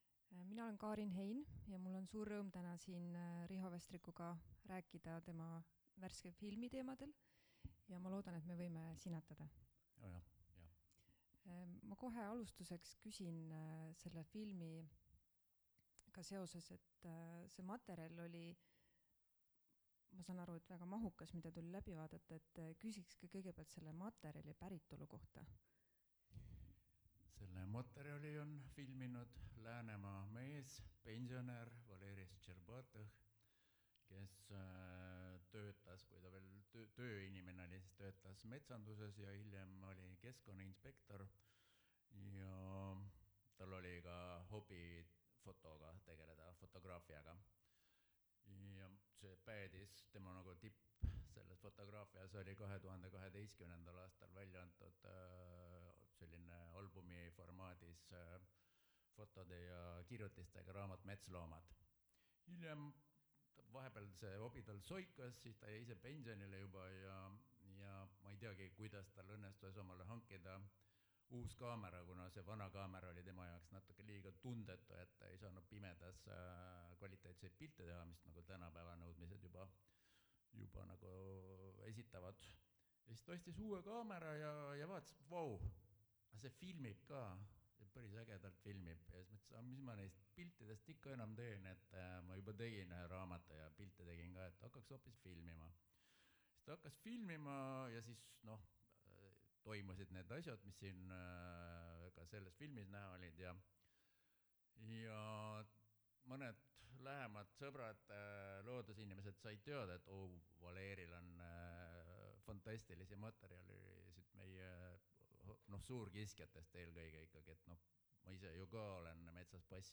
NB! Vestluse salvestus sisaldab sisurikkujaid.
kriimsilm_vestlus.mp3